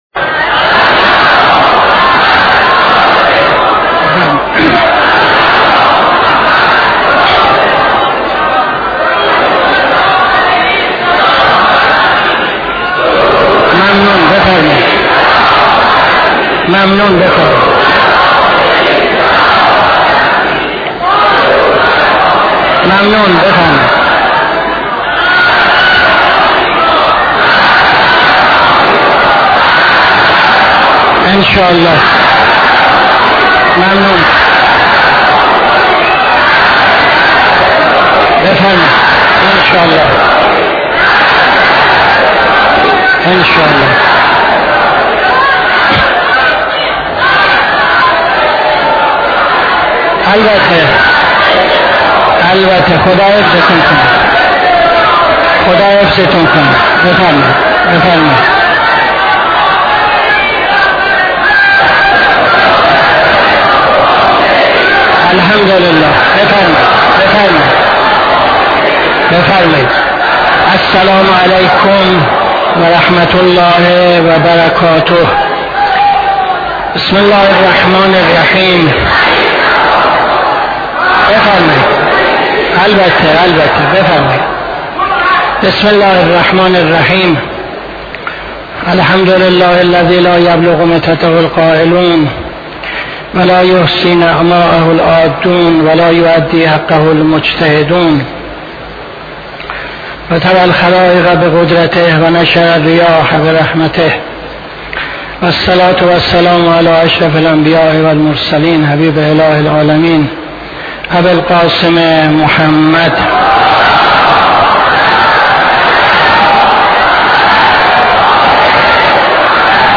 خطبه اول نماز جمعه 22-03-77